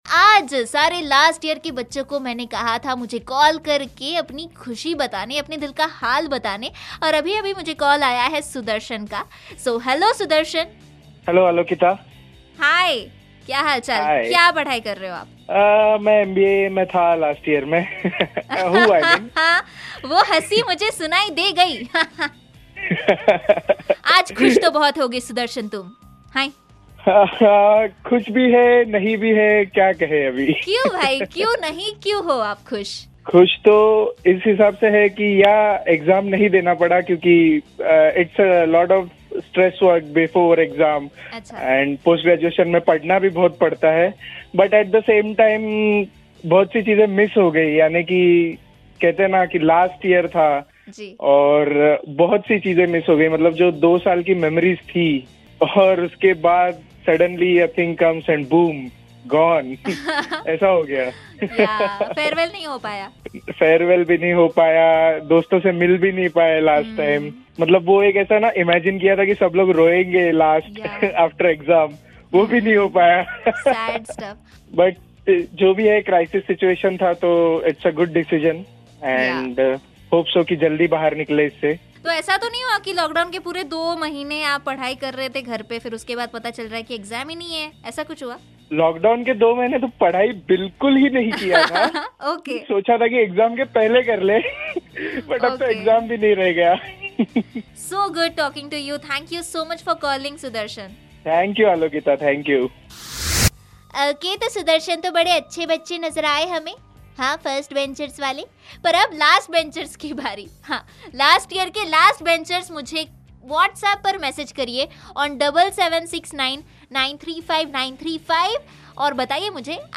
LAST YEAR STUDENTS - LISTENER'S CALL (GOT NO CHANCE TO SAY GOOD BYE TO FRIENDS)